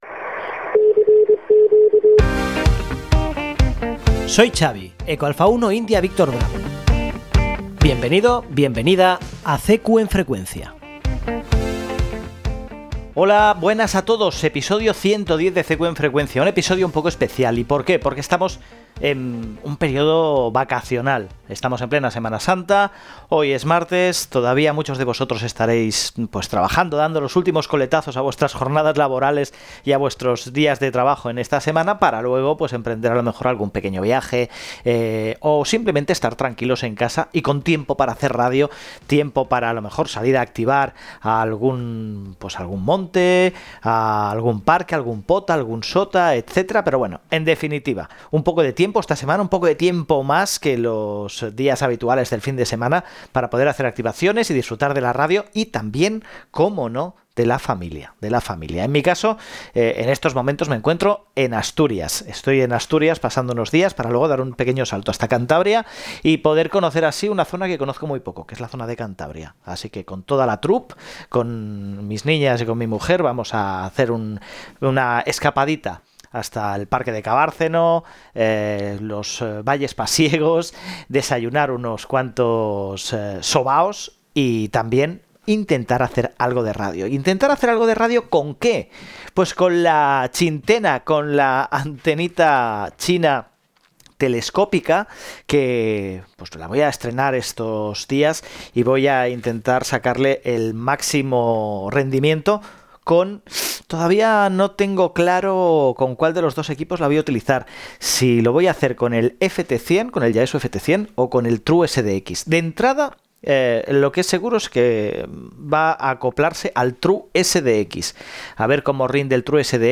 En este episodio más corto y relajado —grabado fuera de casa, en modo vacacional— comparto algunas ideas y novedades frescas del mundo de la radioafición: